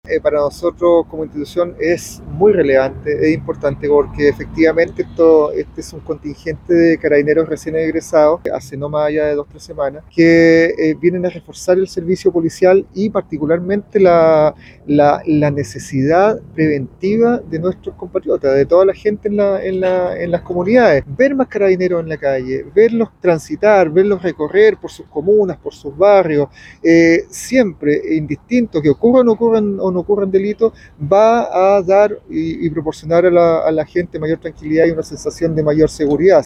Por su parte, el jefe de la Zona de Carabineros “Los Lagos”, general Héctor Valdés destacó que este contingente viene a reforzar el servicio policial y la necesidad preventiva, ya que su presencia en las calles siempre, va a proporcionar mayor seguridad a la comunidad.